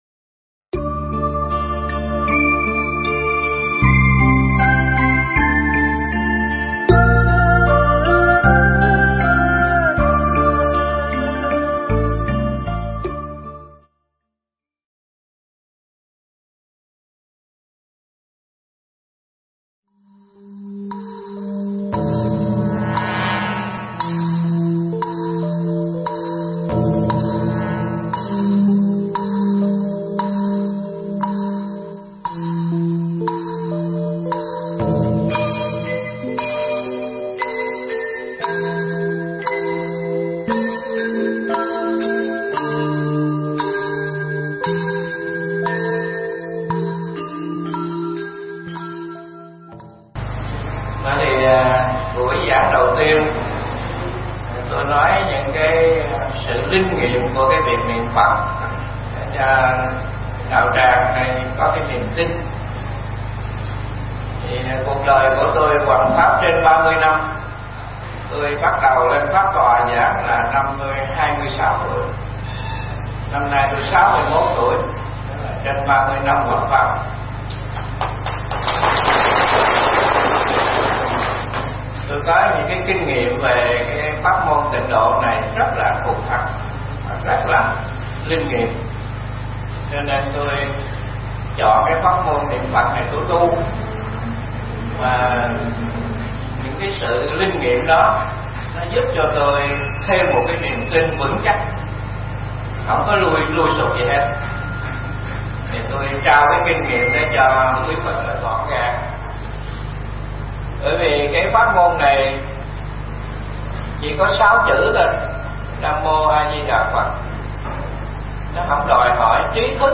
Nghe Mp3 thuyết pháp Sự Mầu Nhiệm Của Công Đức Niệm Phật
Mp3 Pháp thoại Sự Mầu Nhiệm Của Công Đức Niệm Phật